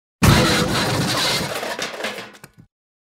Звуки сломанной машины